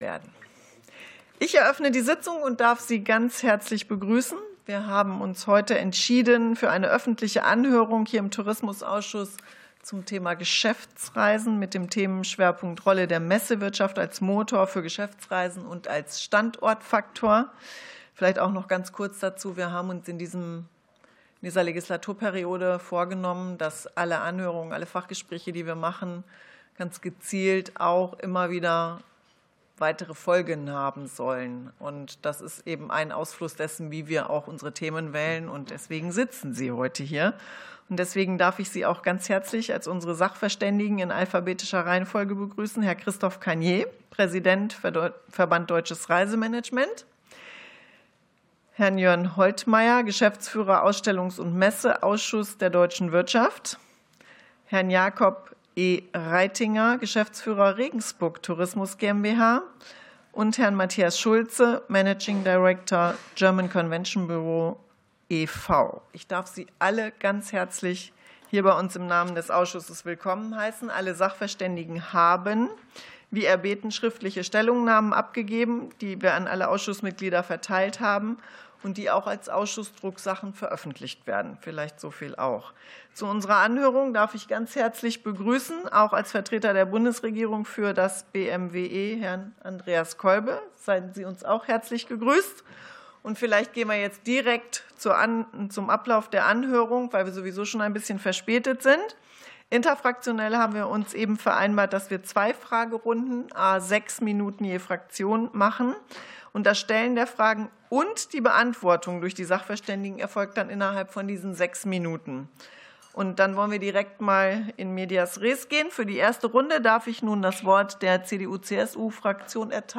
Anhörung des Ausschusses für Tourismus